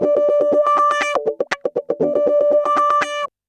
Index of /90_sSampleCDs/USB Soundscan vol.04 - Electric & Acoustic Guitar Loops [AKAI] 1CD/Partition C/04-120GROWAH